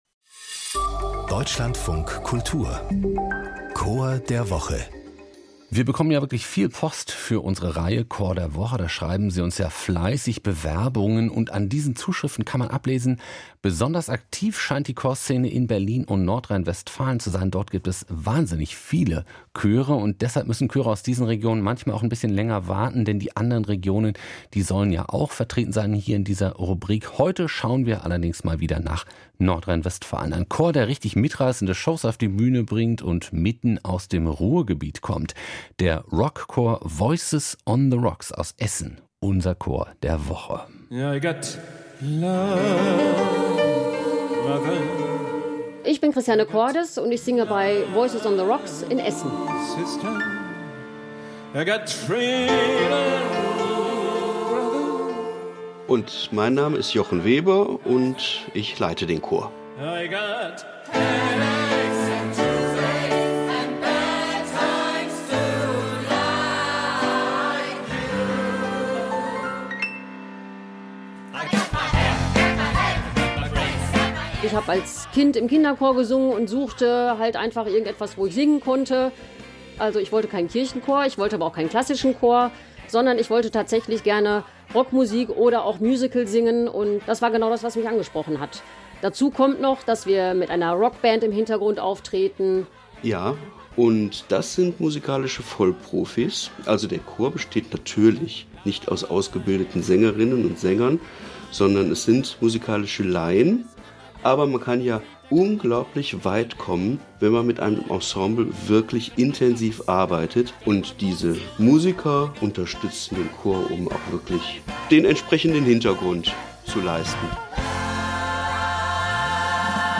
Hier stellen wir Ihnen jede Woche einen Chor vor.